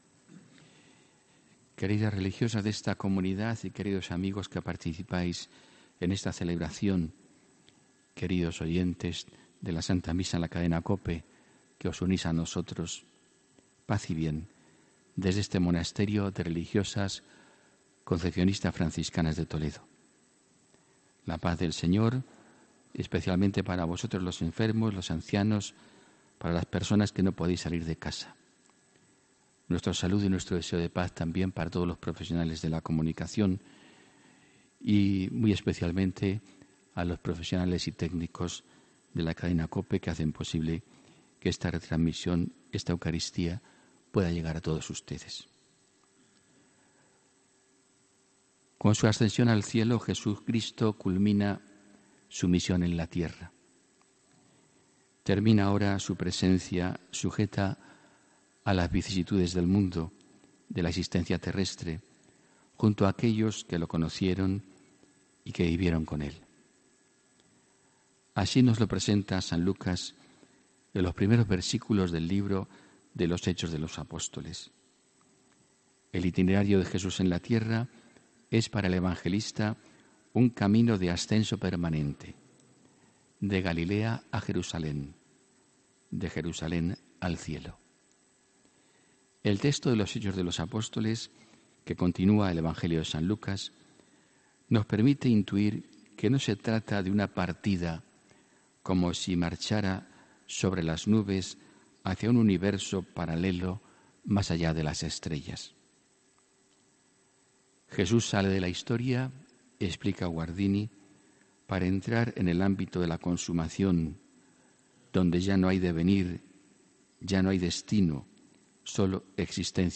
HOMILÍA 13 MAYO 2018